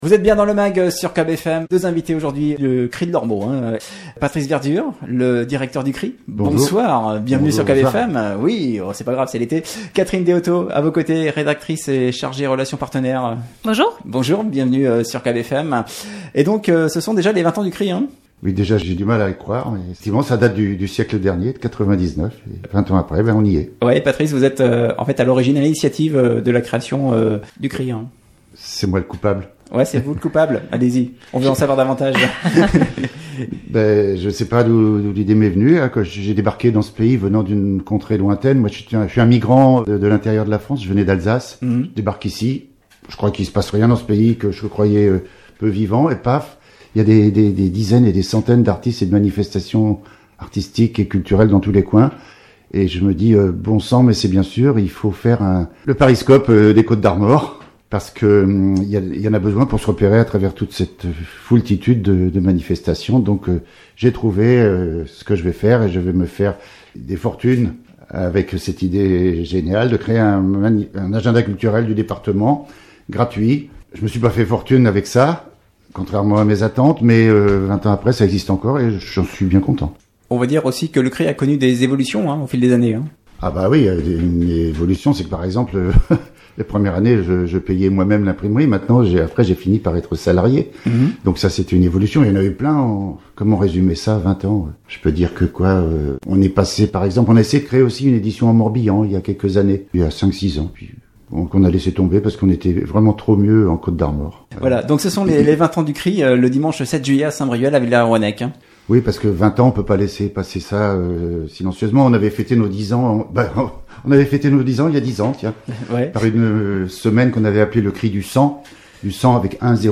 Invités du Mag hier soir